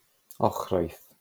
Pentrefan yng nghymuned Gorllewin Rhisga, Bwrdeistref Sirol Caerffili, Cymru, yw Ochrwyth ("Cymorth – Sain" ynganiad ); (Saesneg: Ochrwyth).[1] Mae'n rhan o sir hanesyddol Sir Fynwy.